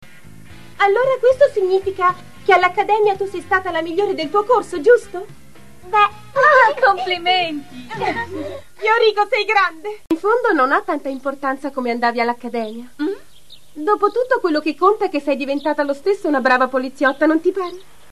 nel cartone animato "Sei in arresto!", in cui doppia Mila.